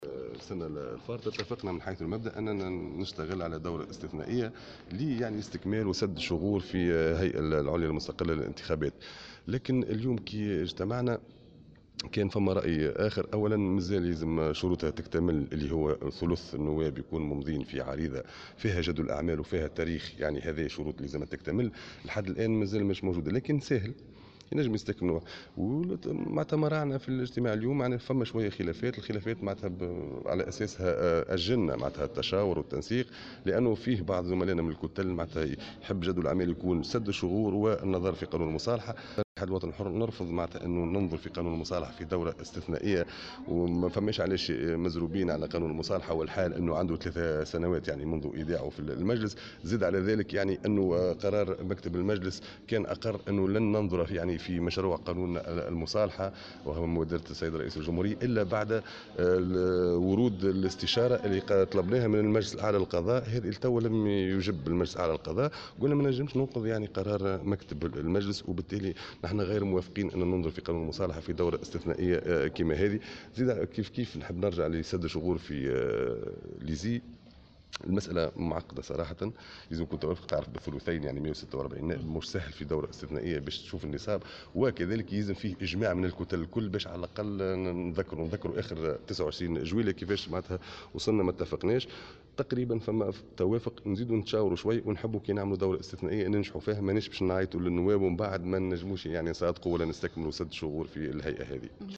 وأضاف في تصريح لمراسل "الجوهرة أف أم" أن مكتب المجلس كان قد أقرّ بأنه لن يتم النظر في مشروع هذا القانون إلا بعد ورود الاستشارة من المجلس الأعلى للقضاء.